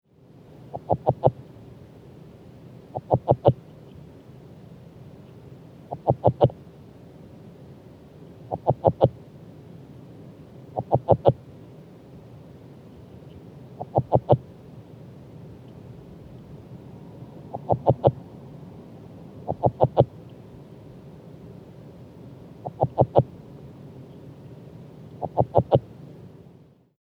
The following recordings were made during an afternoon in early March in an artificial pond in Humboldt County.
All sounds were recorded with a hydrophone, which is an underwater microphone.
Sound This is a 27 second continuous underwater recording of the advertisement calls of a single frog.